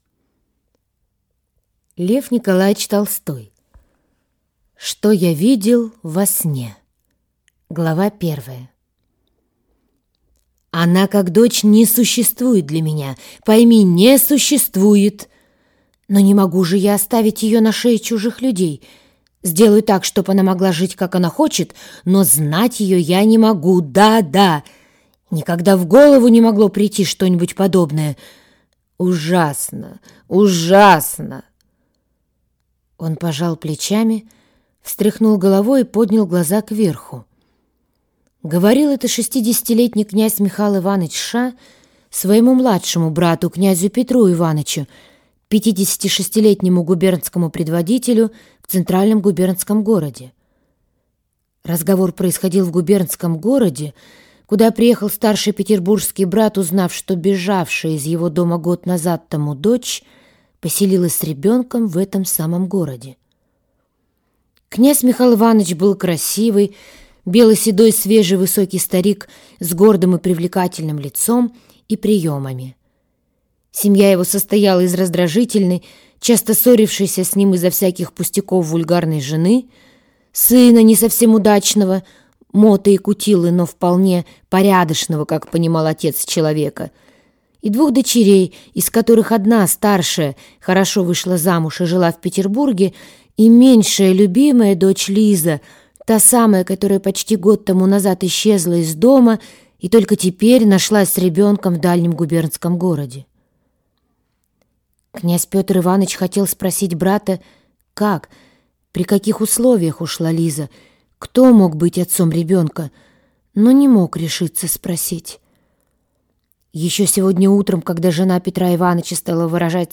Аудиокнига Что я видел во сне…